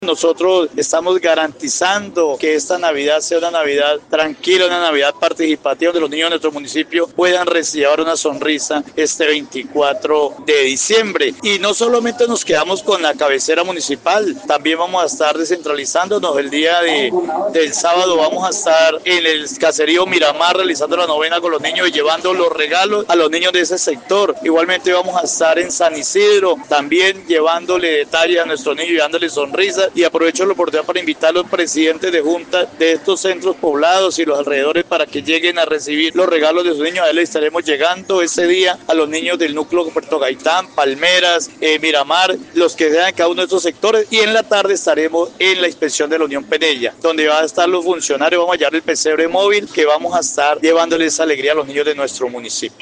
Así lo dio a conocer Federico Alviz, alcalde del municipio de La Montañita.